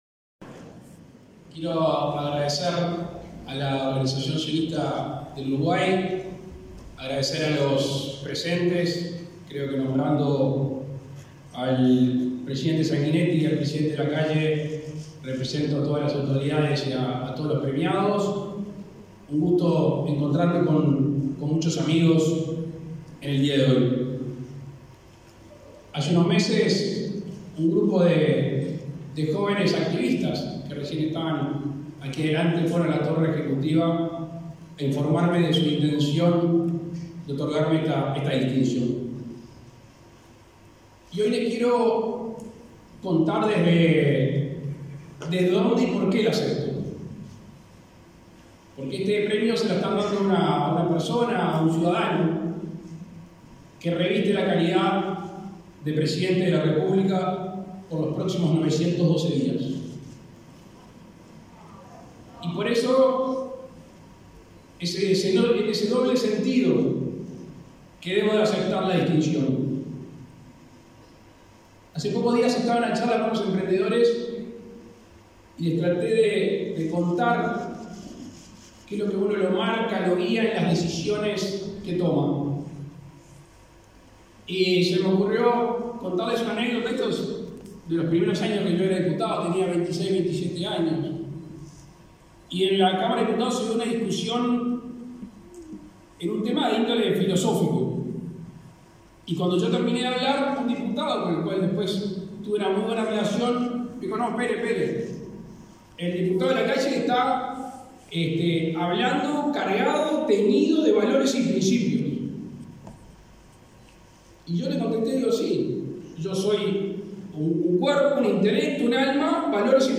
Palabras del presidente de la República, Luis Lacalle Pou
En la ceremonia, el mandatario agradeció la distinción.